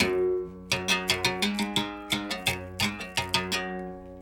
32 Berimbau 06.wav